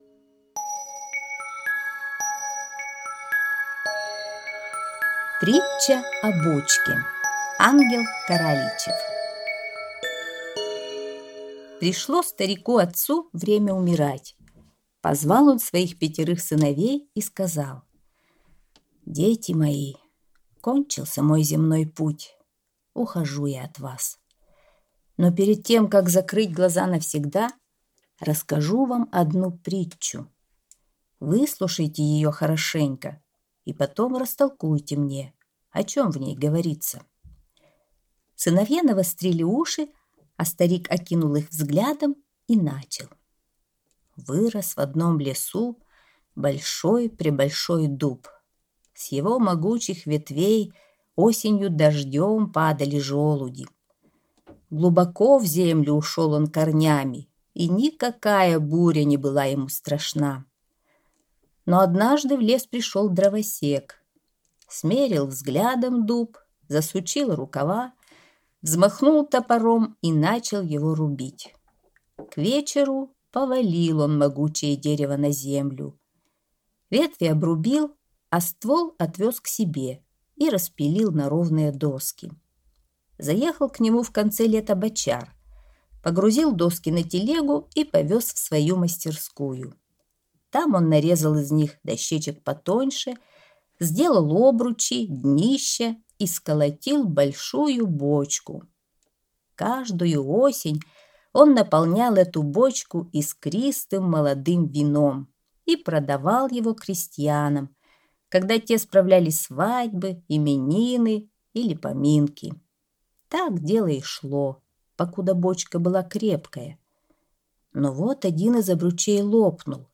Притча о бочке – Каралийчев А. (аудиоверсия)